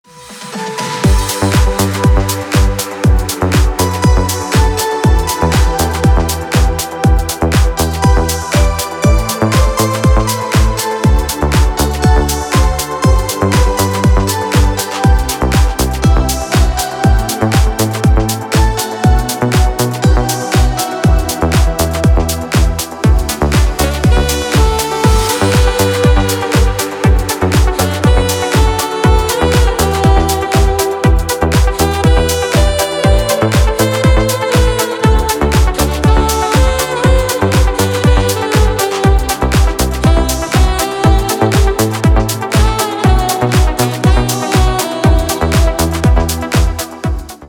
теги: красивый рингтон
клубные рингтоны 2024 скачать на телефон онлайн.